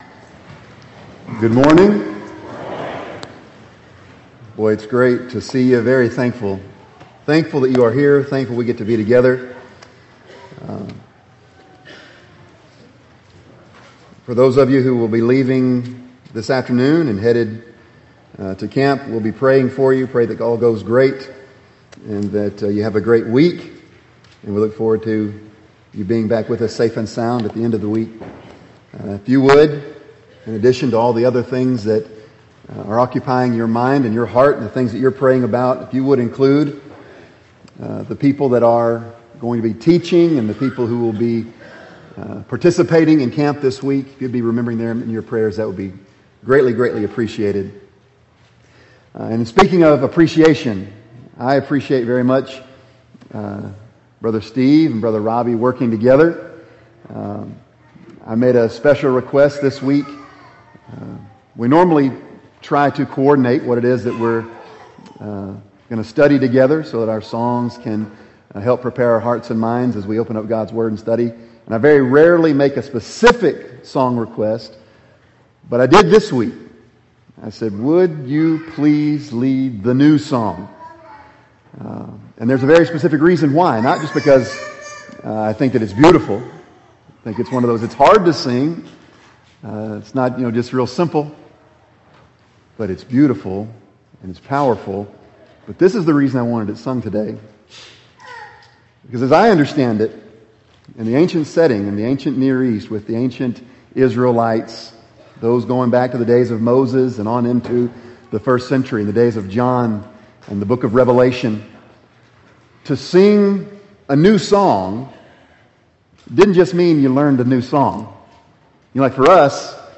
Bible Text: Revelation 19:11-15 | Preacher